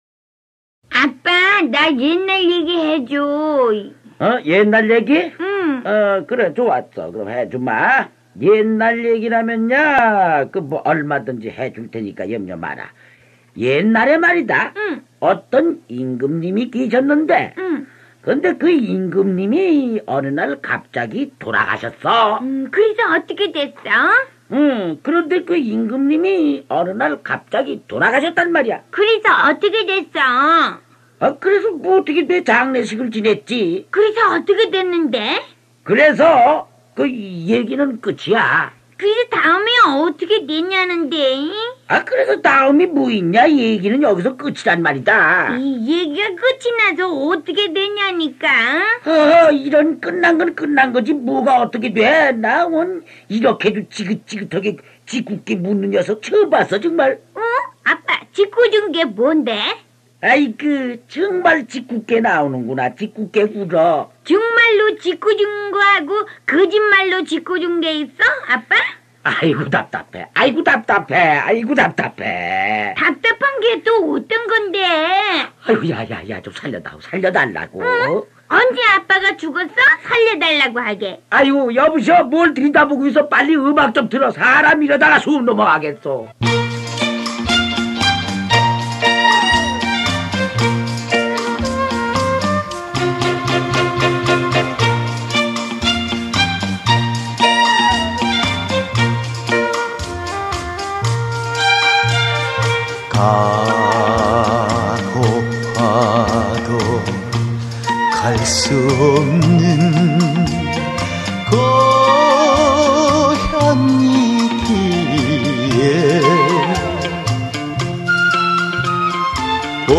최근 2년간의 히트곡만으로 코메디와 함께 엮은 흥미진진한 호화결정판